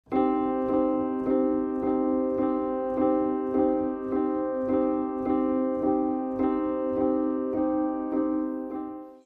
Пишу минус, не могу понять что звучит вместе с фоно.